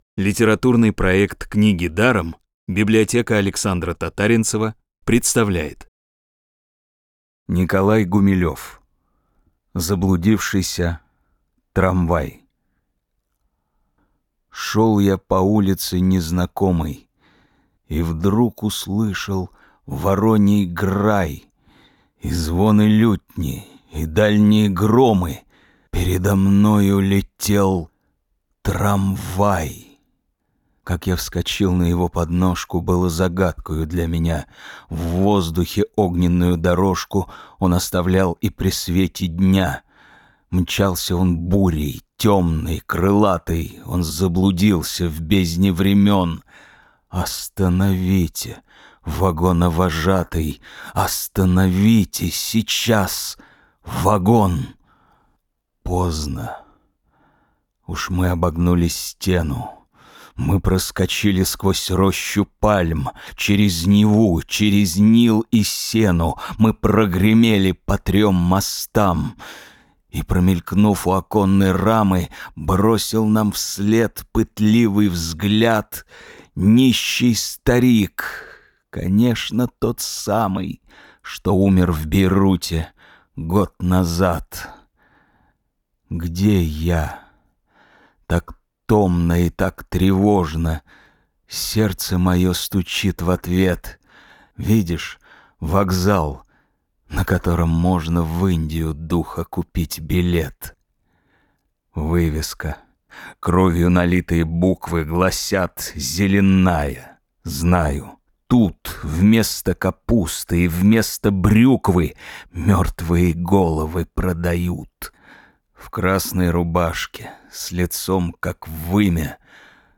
Аудиокнига «Заблудившийся трамвай». Классическую литературу в озвучке «Рексквер» легко слушать благодаря профессиональной актерской игре и качественному звуку.